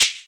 • Original Shaker Hi Hat E Key 15.wav
Royality free shaker percussion tuned to the E note. Loudest frequency: 4523Hz
original-shaker-hi-hat-e-key-15-17w.wav